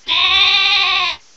The cries from Chespin to Calyrex are now inserted as compressed cries
wooloo.aif